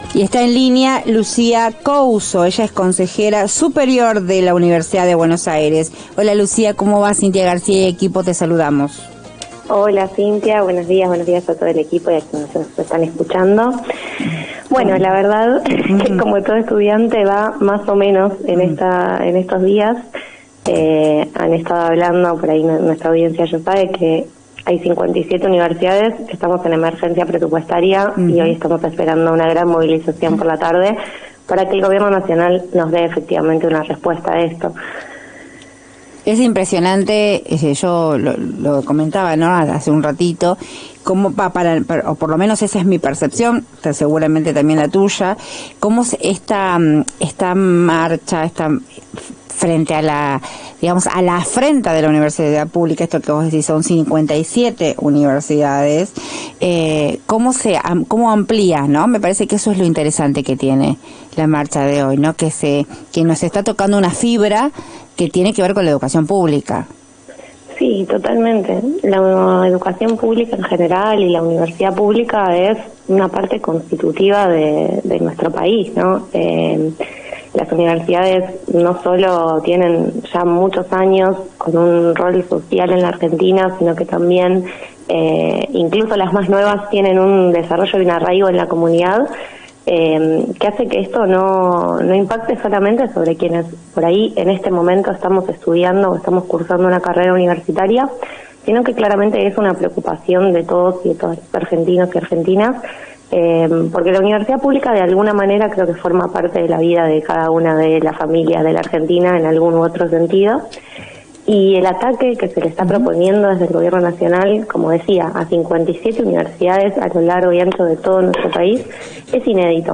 En diálogo con La Garcia por AM750.